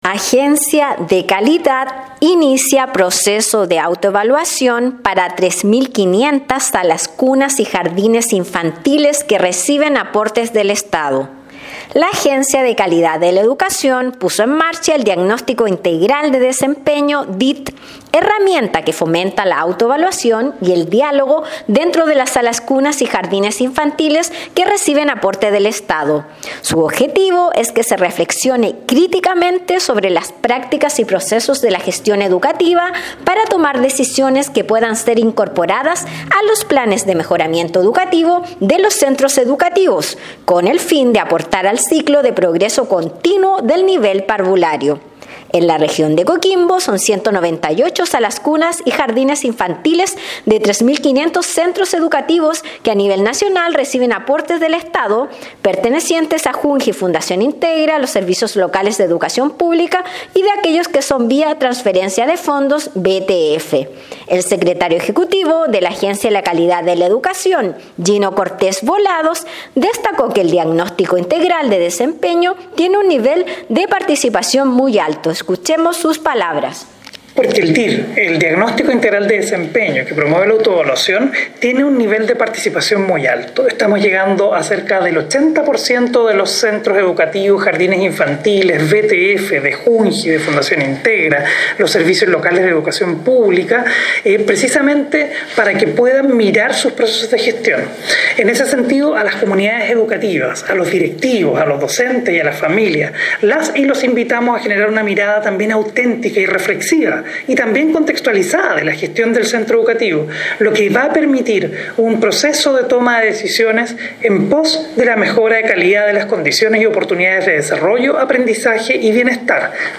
Despacho-Radial-Agencia-de-Calidad-inicia-proceso-de-autoevaluacion-para-3500-salas-cunas-y-jardines-infantiles_.mp3